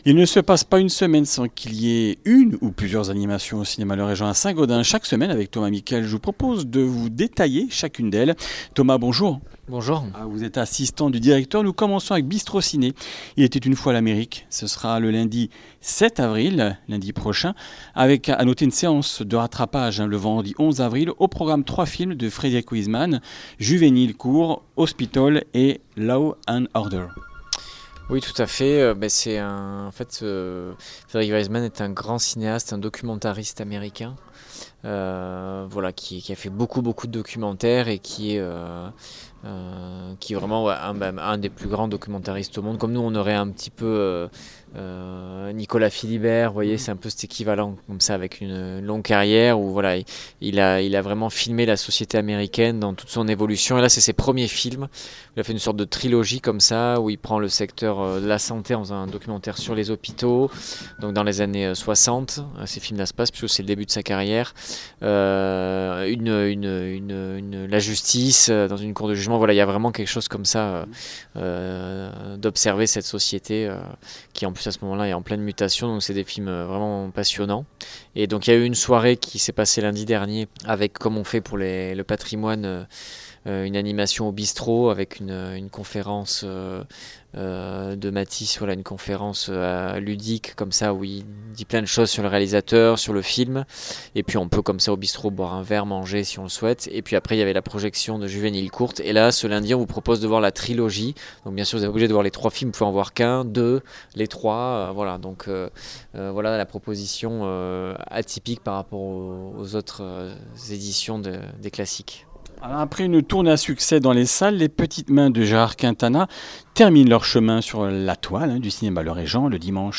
Comminges Interviews du 03 avr.